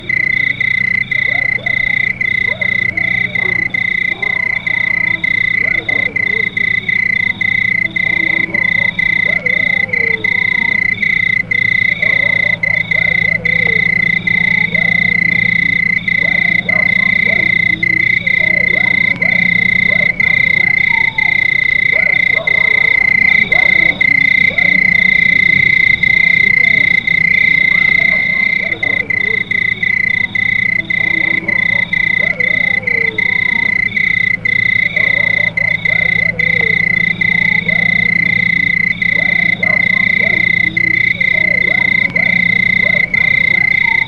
AMBIANCES SONORES DE LA NATURE